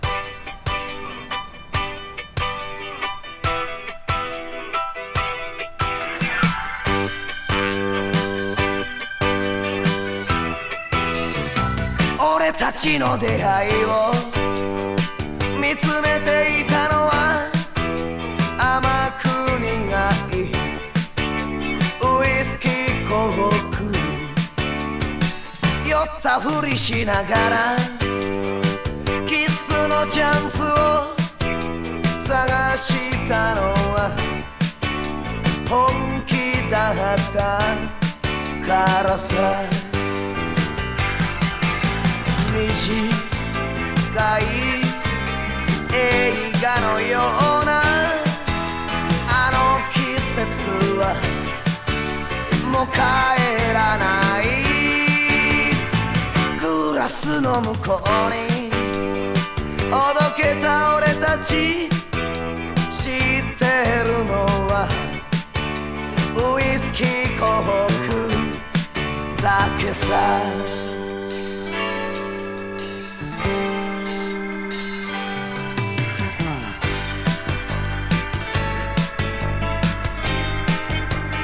不安定な音程もご愛敬な歌声が最高。